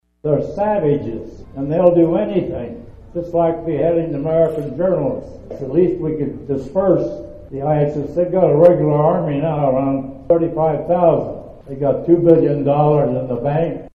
During this week’s tour of Southwest Kansas former Senator Bob Dole spoke about why the US needs to take ISIS seriously.